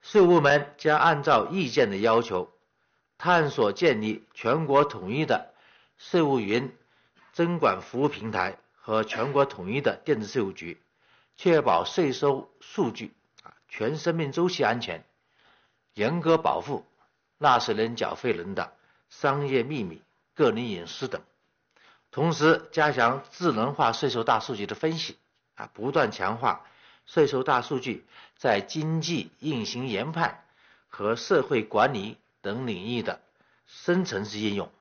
近日，国务院新闻办公室举行新闻发布会，国家税务总局相关负责人介绍《关于进一步深化税收征管改革的意见》（以下简称《意见》）有关情况。会上，国家税务总局总审计师饶立新介绍，目前203项税费业务可全程网上办。